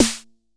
Tr8 Snare 02.wav